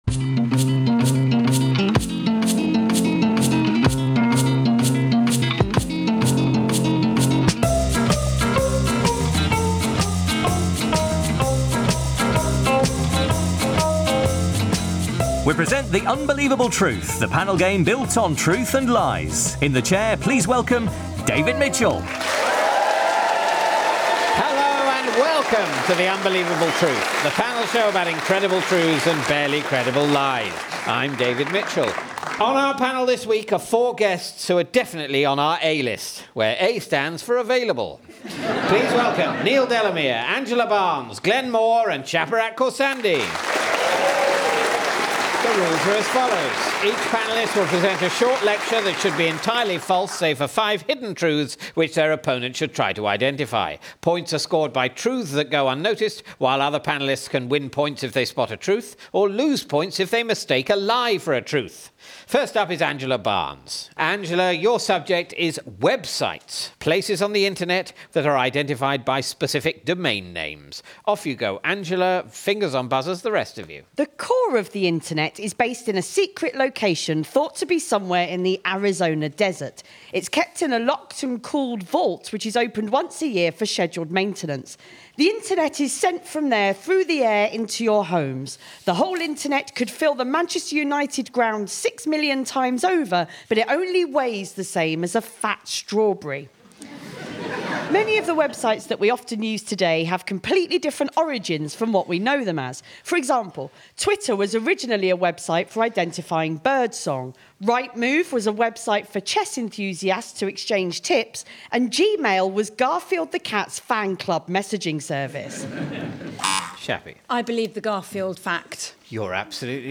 Angela Barnes, Glenn Moore, Shaparak Khorsandi and Neil Delamere are the panellists obliged to talk with deliberate inaccuracy on subjects as varied as websites, cartoons, Prince Harry and teeth.